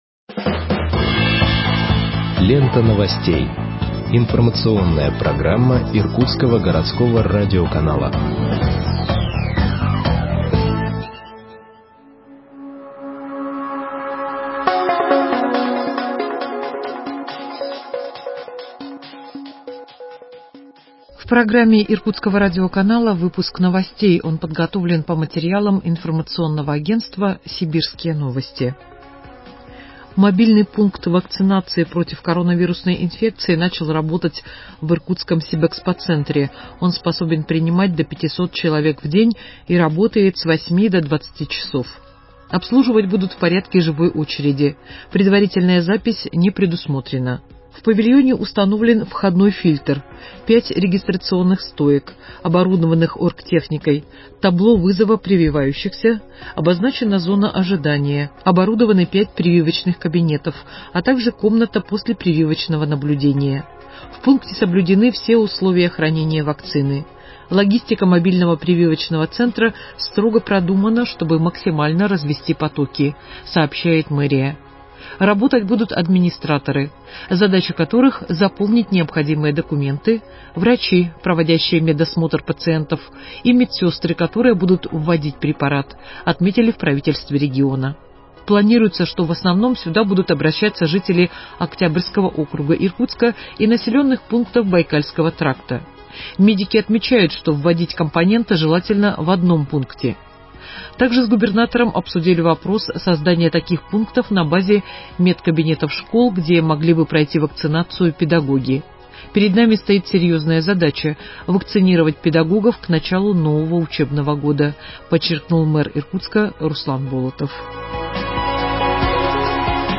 Выпуск новостей в подкастах газеты Иркутск от 19.07.2021 № 2